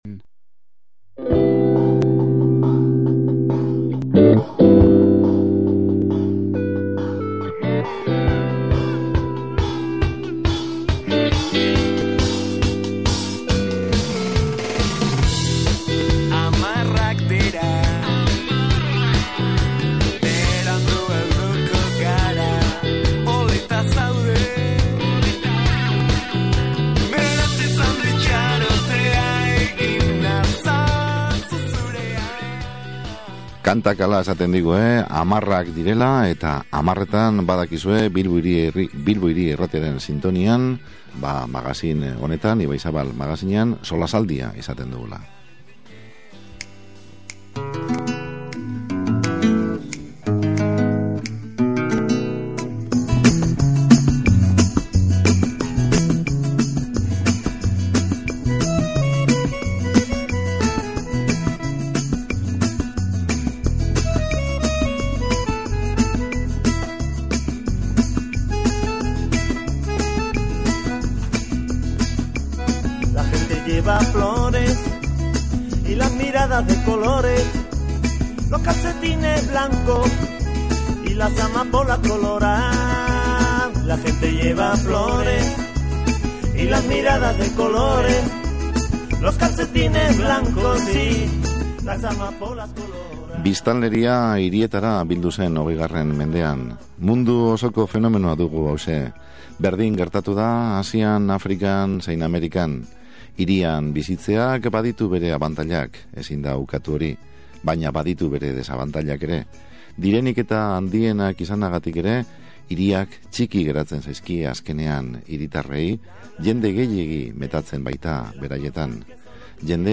SOLASALDIA: “Slowcitiak” edo hiri lasaiak